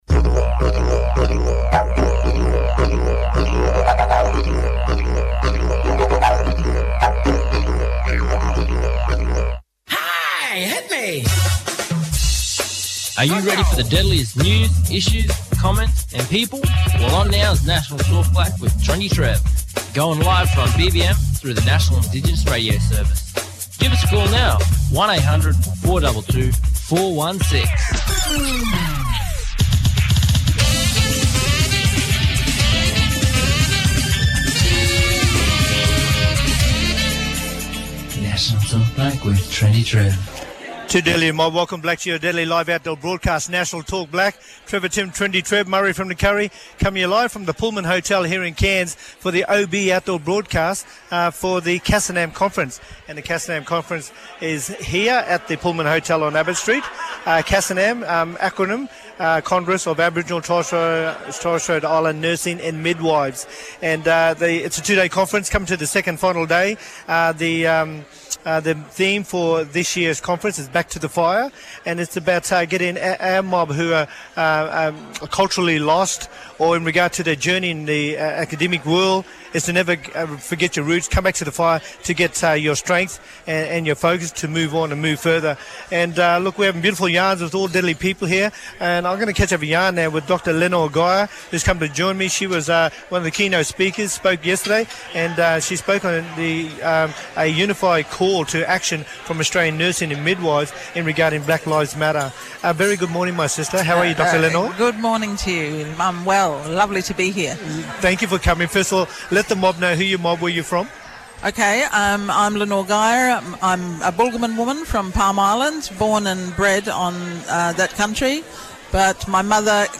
Live from the congress Of Aboriginal and Torres Strait Islander Nurses and Midwives National Conference Series in Cairns